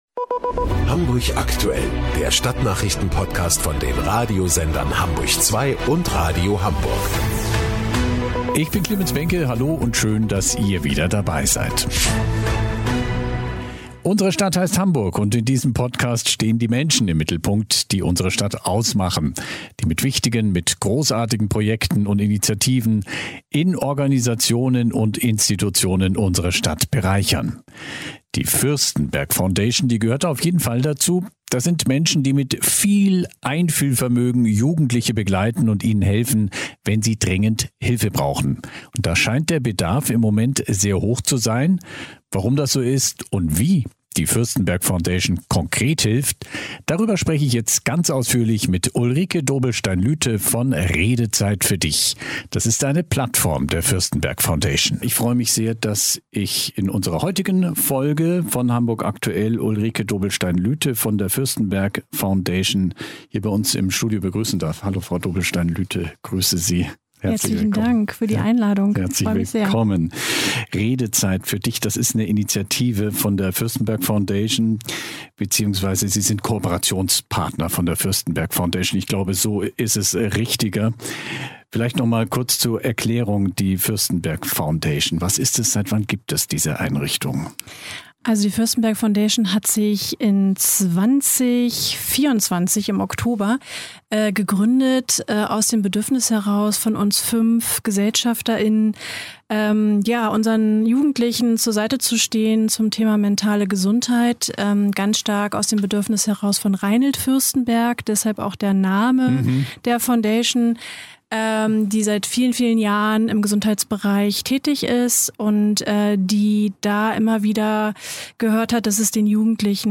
528: Zuhören kann Leben verändern ~ HAMBURG AKTUELL - Der Stadtnachrichten Podcast von Radio Hamburg und HAMBURG ZWEI Podcast